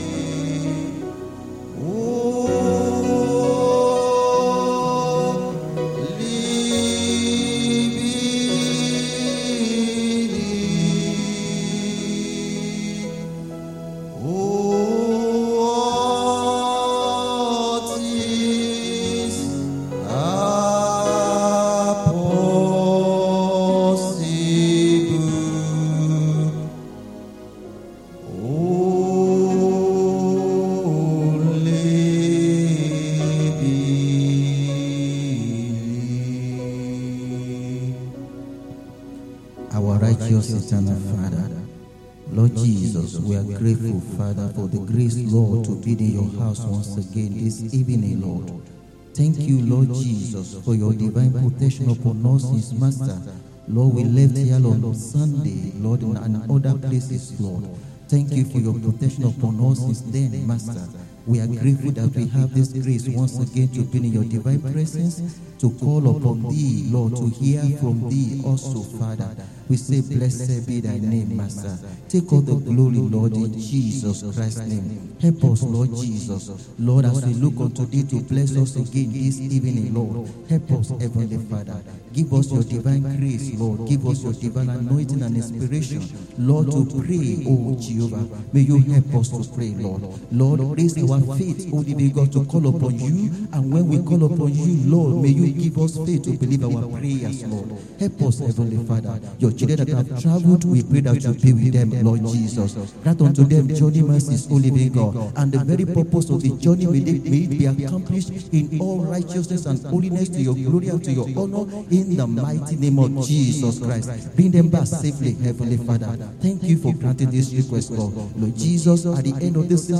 Wed. Prayer Meeting